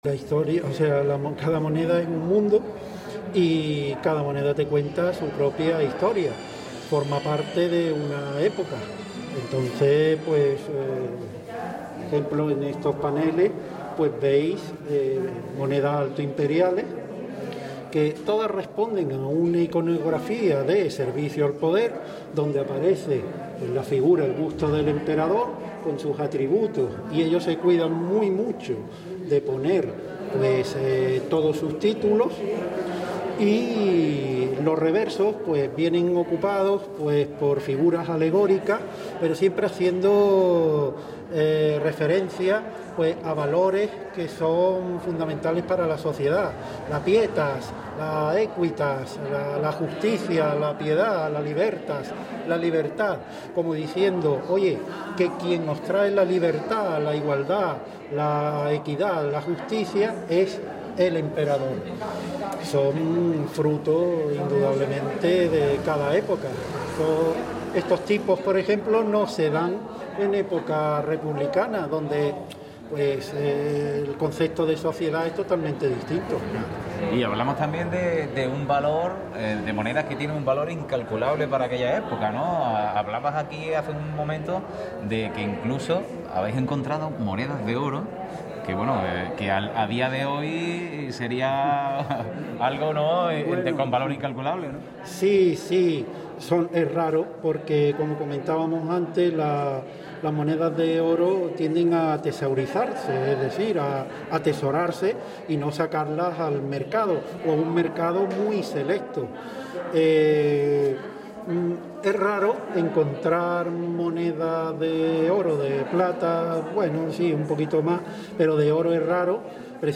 Inaugurada en la galería del Palacio la exposición “Pecvnia”, sobre monedas desde época romana